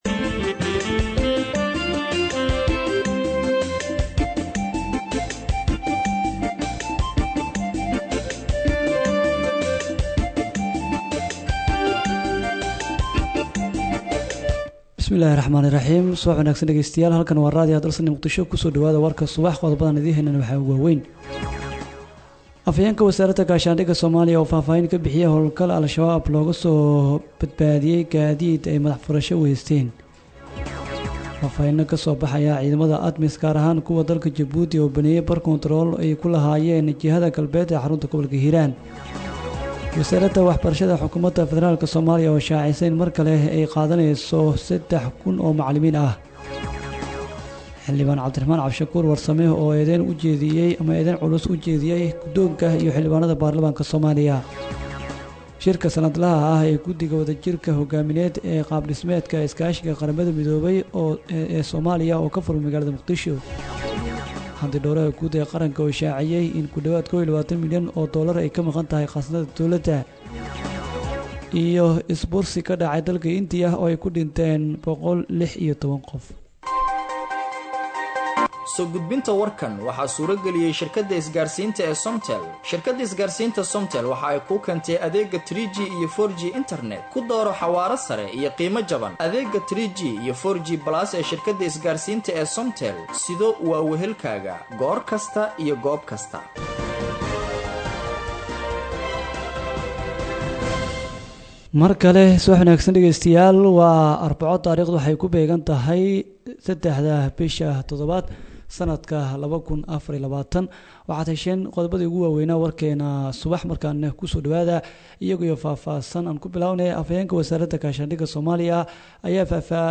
Dhageyso:-Warka Subaxnimo Ee Radio Dalsan 03/07/2024
HalkanÂ Ka Dhageyso WarkaÂ Subaxnimo Ee Radio Dalsan:-